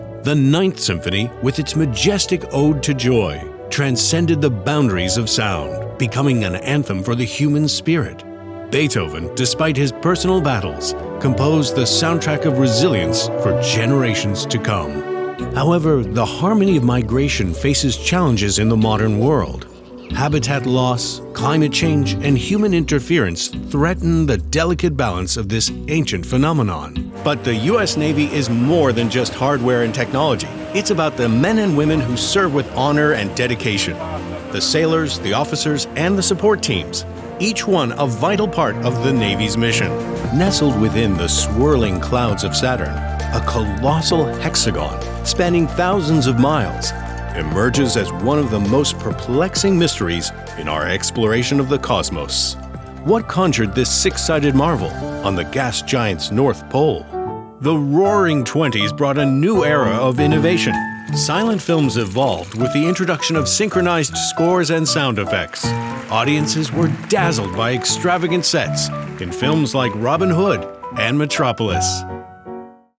Professional voice over studio with GIK acoustic treatment.
Sprechprobe: Sonstiges (Muttersprache):